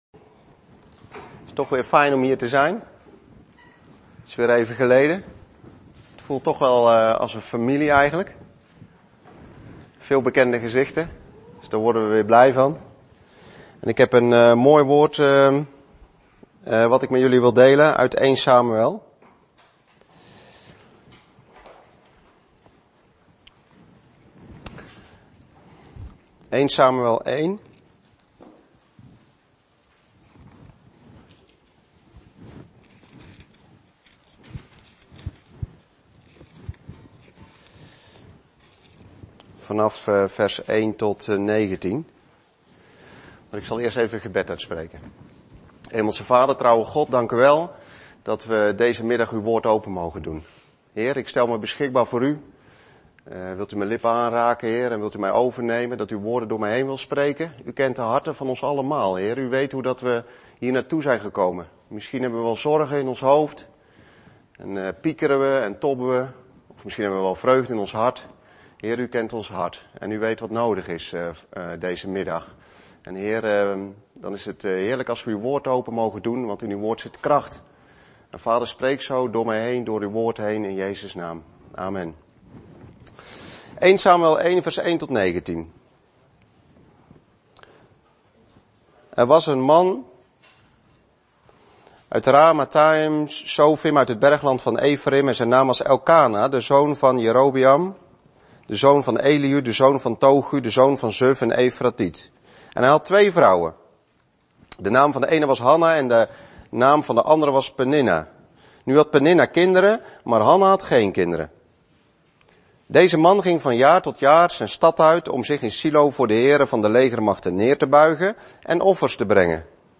Een preek over 'Wie ben jij?'.